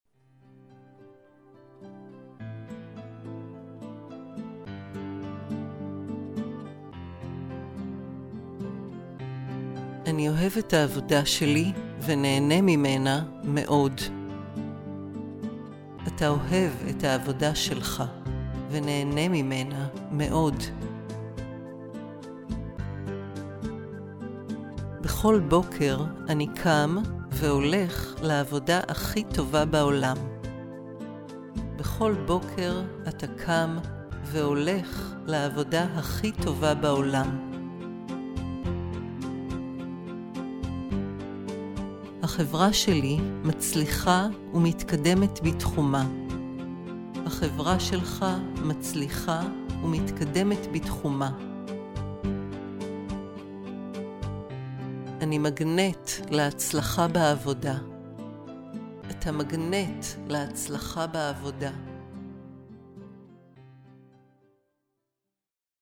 • בנוסף לקלטות הסאבלימינליות, תקבלו גם קובץ של המסרים הגלויים, כפי שהוקלטו במקור בשילוב מוזיקה נעימה.
1. המדיטציה הגלויה: להצלחה בקריירה לשכירים (לגברים ולנשים בנפרד).
דוגמה ההצהרות הגלויות להצלחה בקריירה עבור שכירים – לגברים:
הצהרות גלויות להצלחה בקריירה לגברים